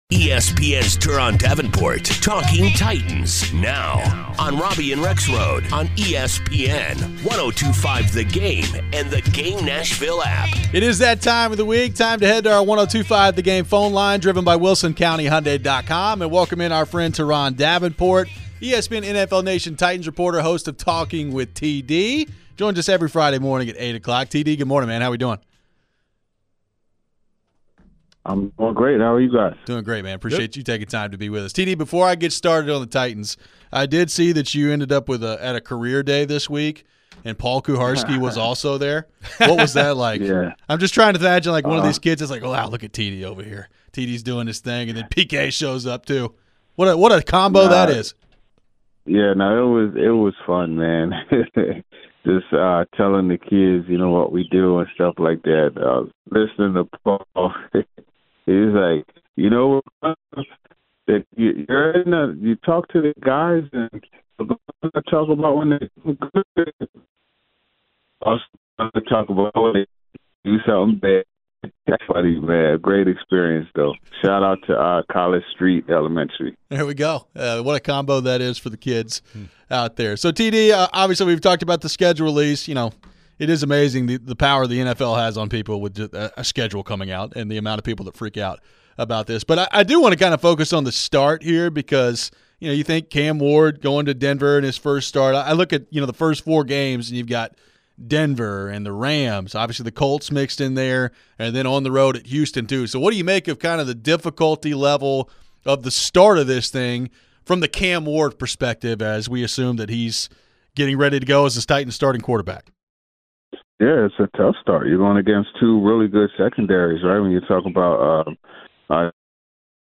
We head back to the phones on the Titans, and how tough will the opening stretch be for Cam Ward?
We have our 'Weekend Winners' segment picking some games. We close out the week with your final phones.